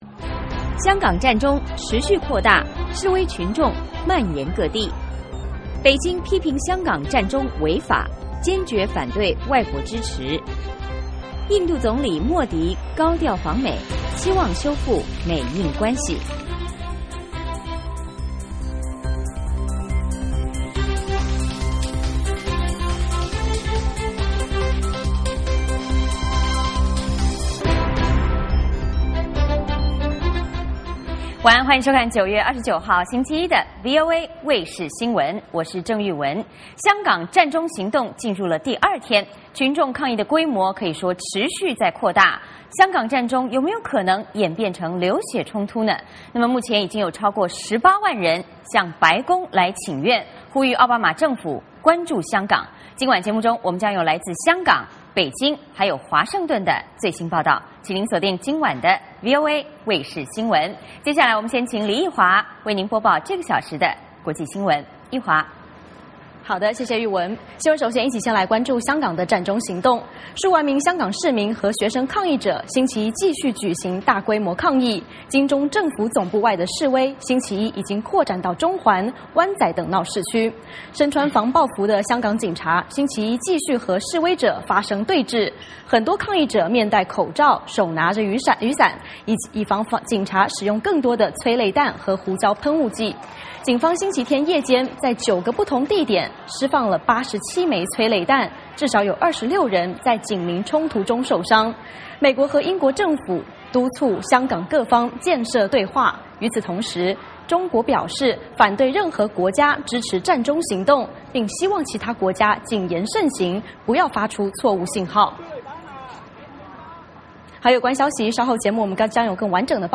VOA卫视电视广播同步播出。香港占中行动进入第二天，群众抗议的规模持续扩大，香港占中是否可能演变成流血冲突，目前已经有超过18万人向白宫请愿，呼吁奥巴马政府关注香港，是节目中我们有来自香港北京和华盛顿的最新报道。